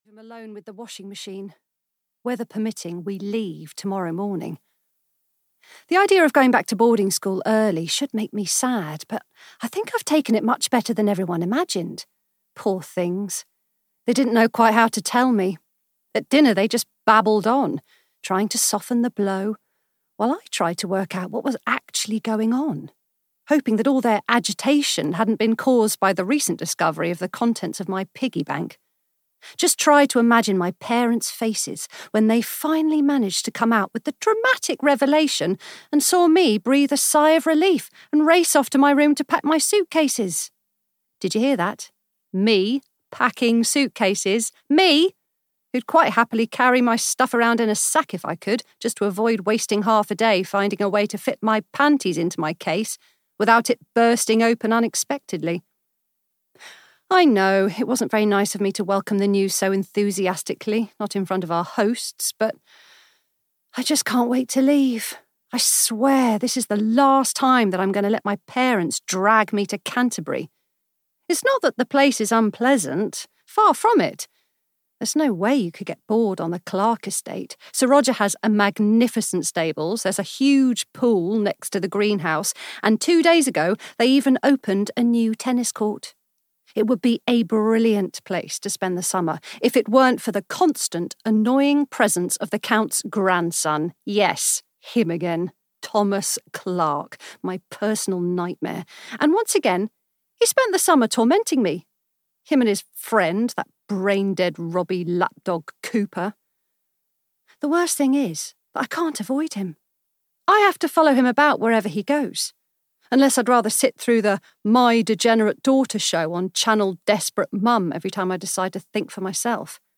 Don't Marry Thomas Clarke (EN) audiokniha
Ukázka z knihy